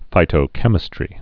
(fītō-kĕmĭ-strē)